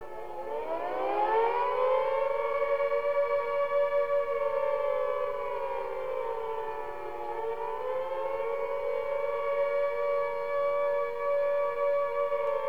Air Raid Warning - Attack